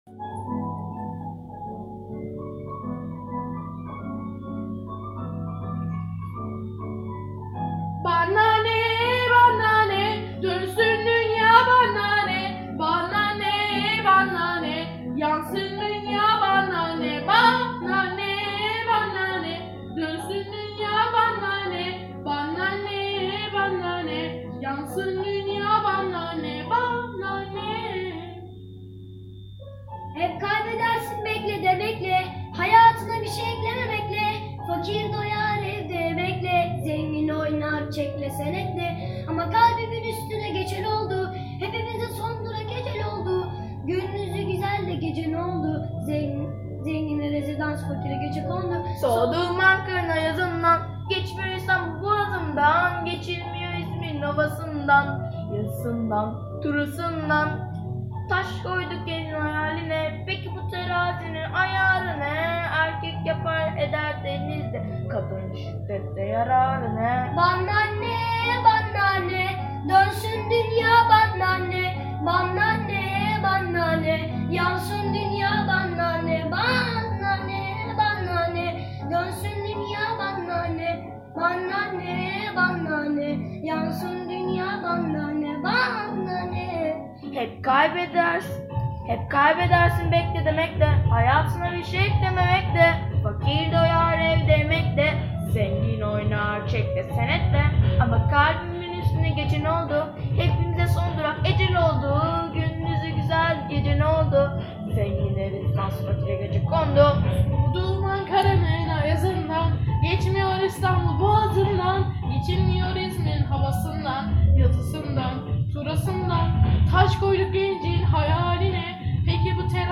Giho Şarkı Yarışması Albümü